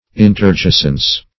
Search Result for " interjacence" : The Collaborative International Dictionary of English v.0.48: Interjacence \In`ter*ja"cence\, Interjacency \In`ter*ja"cen*cy\, n. [See Interjacent .]